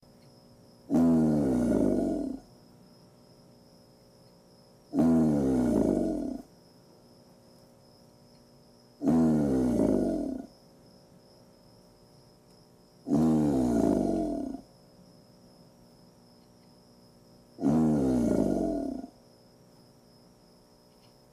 Грозный рёв аллигатора